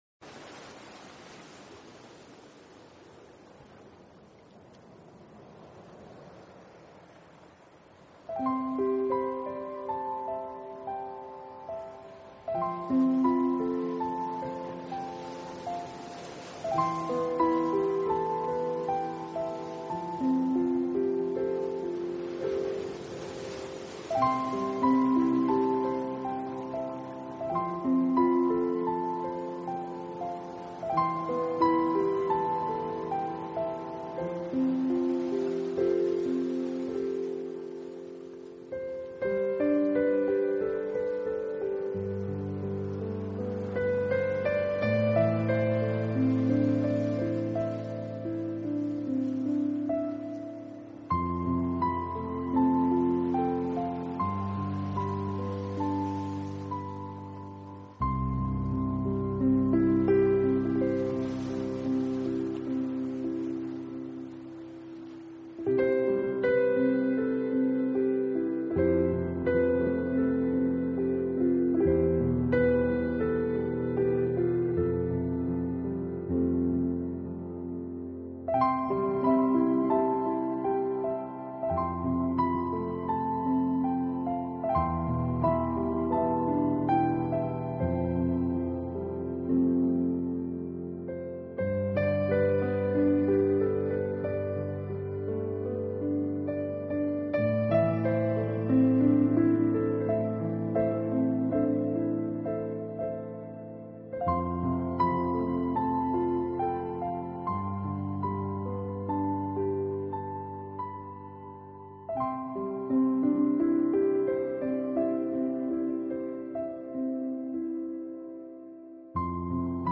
Genre..........: New Age
QUALiTY........: MP3 44,1kHz / Stereo
helps create a relaxing and inspiring atmosphere.